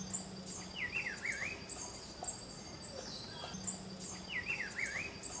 Juan Chiviro (Cyclarhis gujanensis)
Nombre en inglés: Rufous-browed Peppershrike
Localidad o área protegida: Ibague Zona De Boqueron
Condición: Silvestre
Certeza: Vocalización Grabada